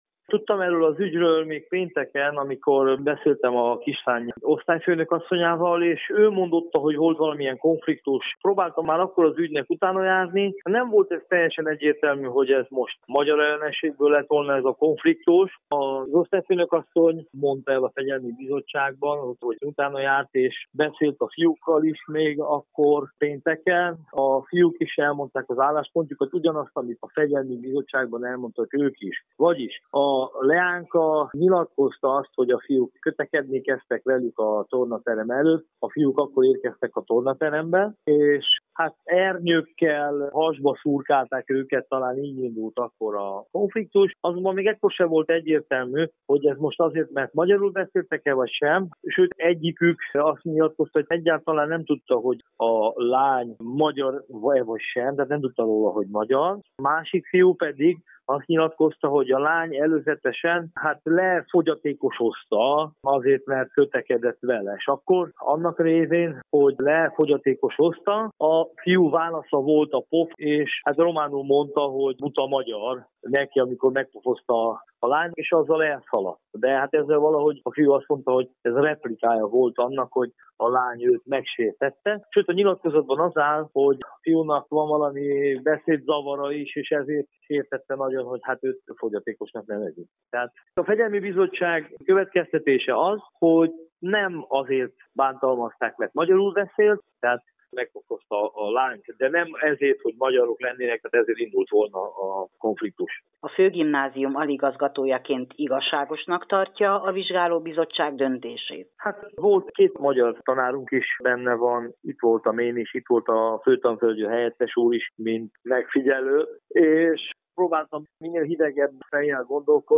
interjút